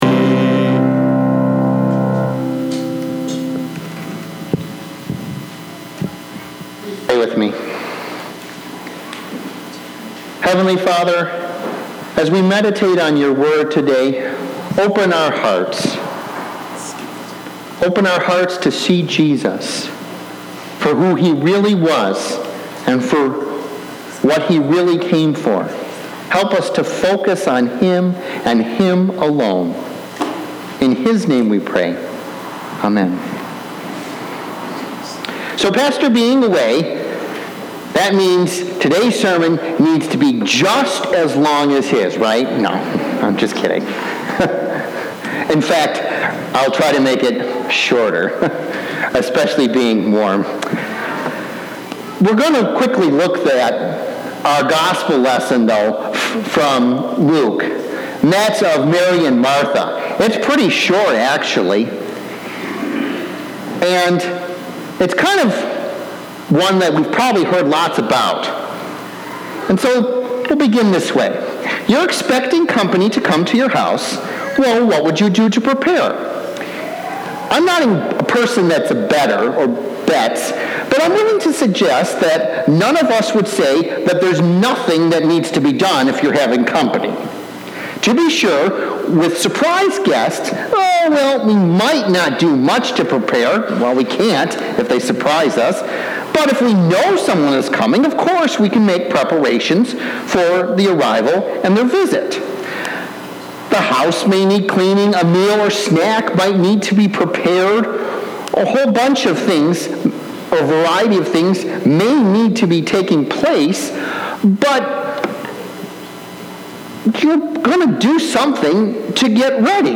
Sermon 7-21-19